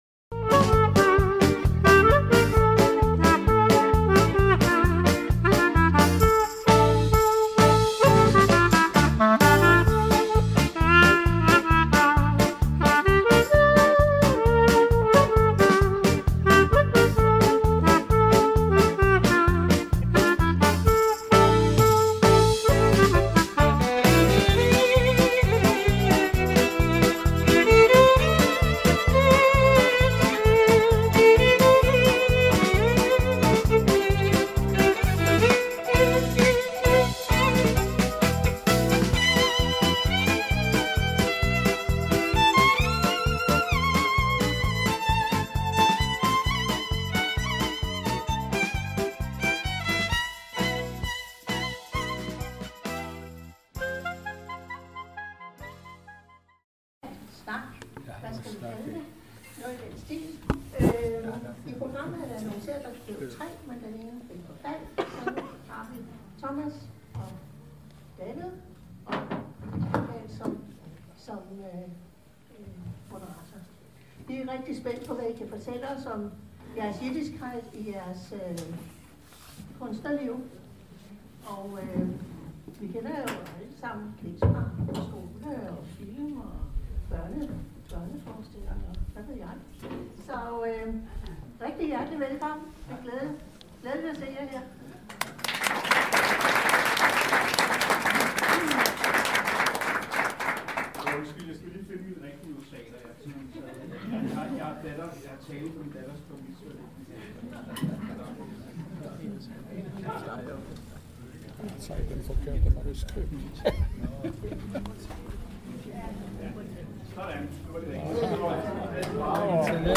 Diskussion over emnet ” Kunsten og den jødiske identitet”